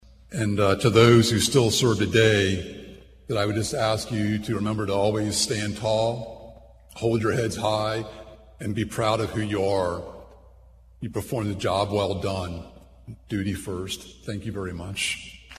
The annual parade and Ceremony of Honor recognized “Veterans of the Cold War.”
Tuner finished his speech with advice for current active duty soldiers: